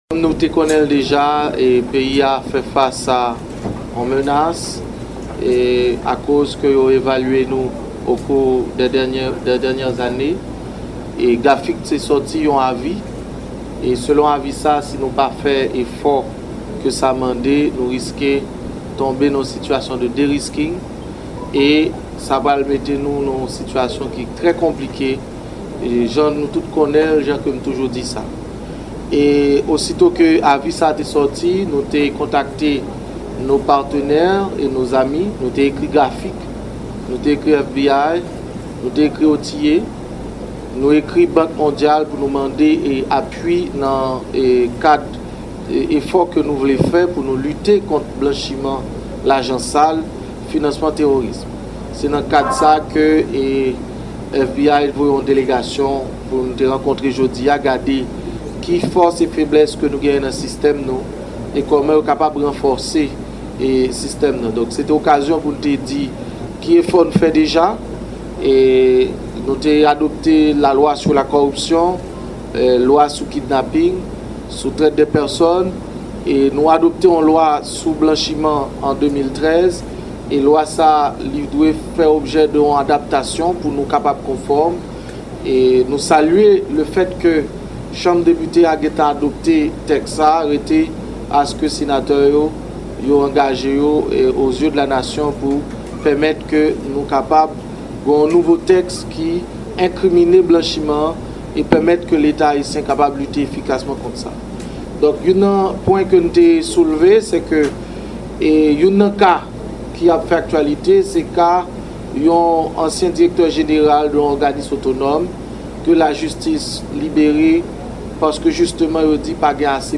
Deklarasyon